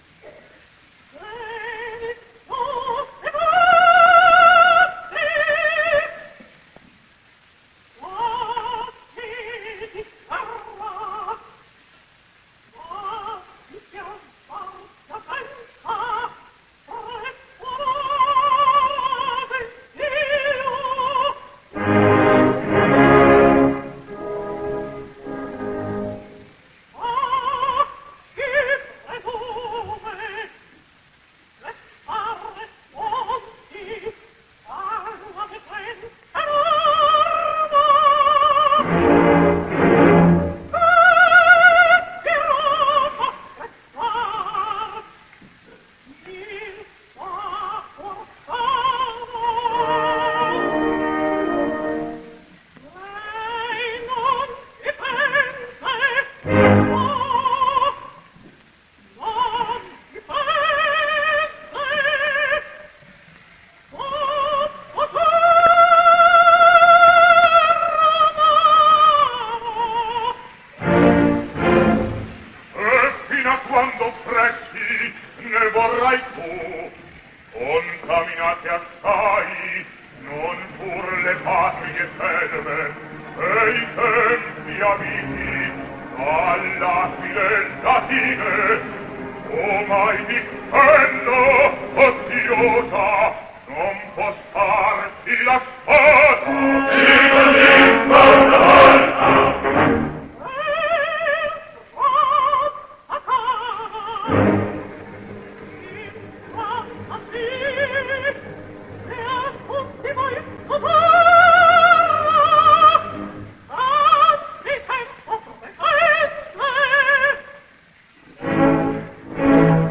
opera completa, registrazione dal vivo.